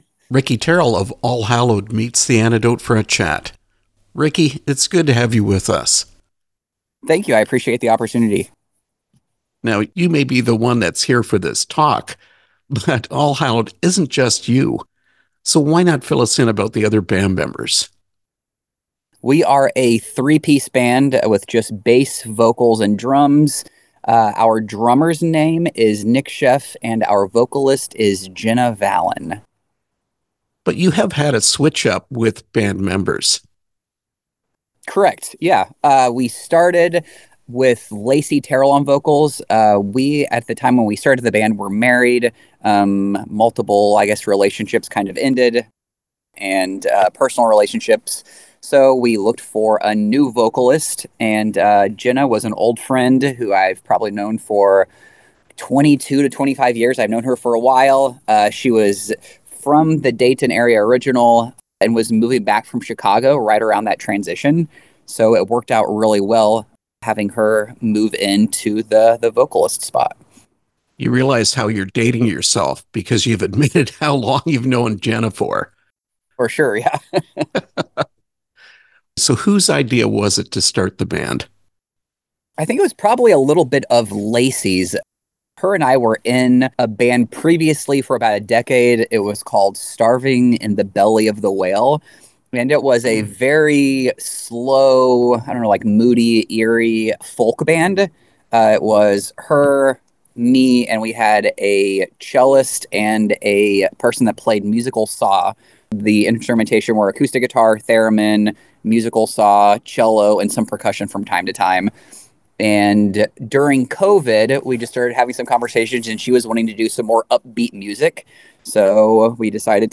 Interview with All Hallowed